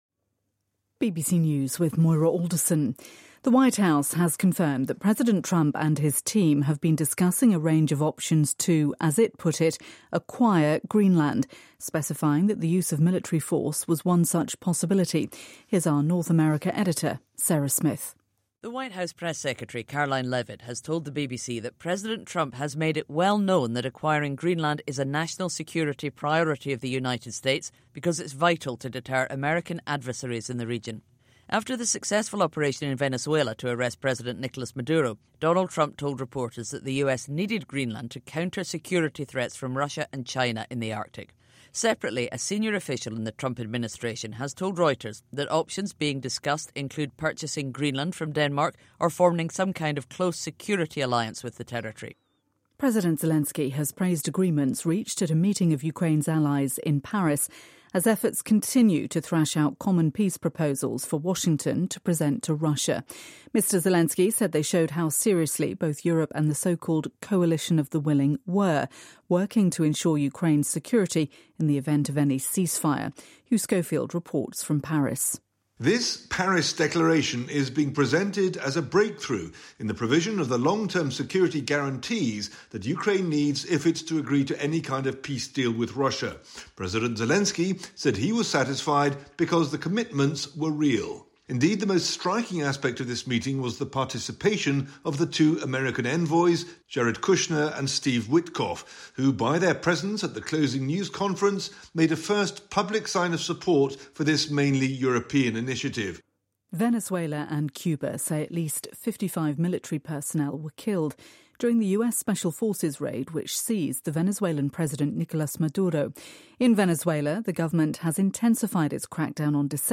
BBC新闻